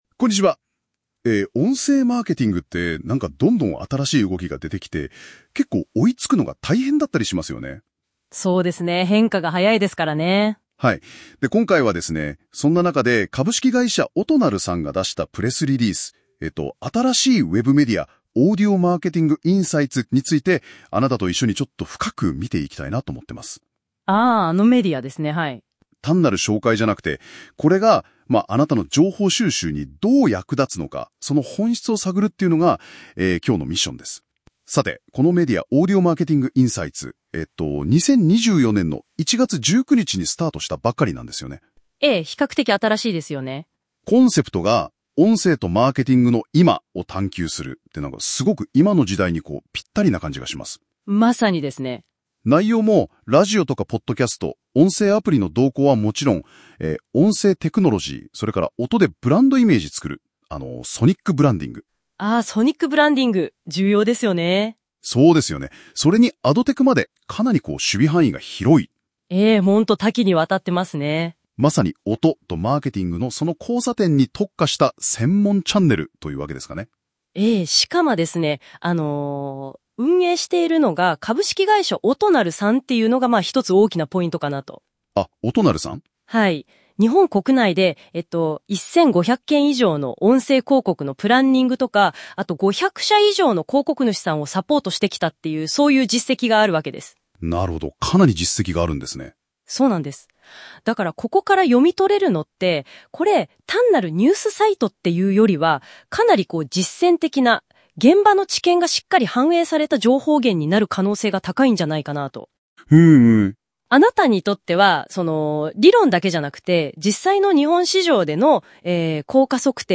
音声概要は、ユーザーがアップロードした資料をもとに、AIが要点を抽出し、2人のAIホストによる自然な会話形式の音声として出力する機能です。ポッドキャストのような自然な音声で情報を聞けるのが特長で、もともとは英語のみに対応していましたが、今回のアップデートで日本語を含む50以上の言語に対応しました。